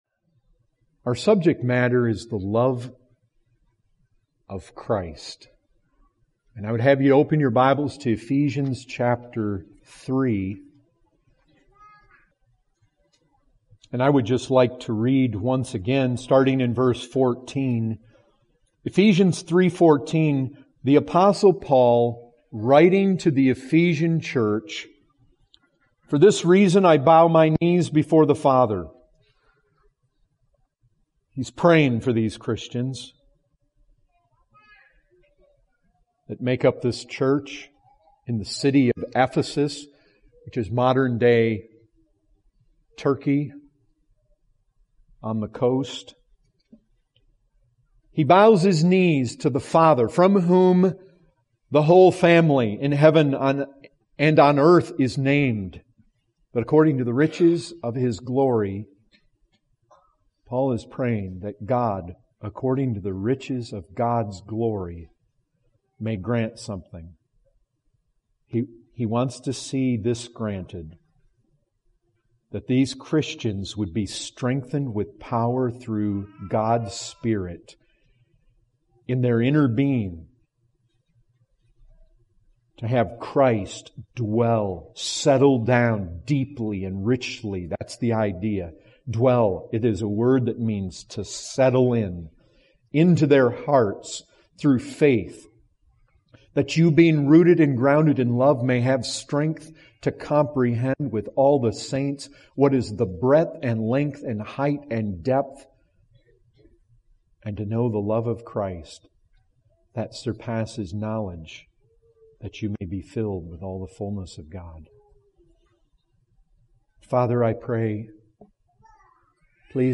2018 Category: Full Sermons Topic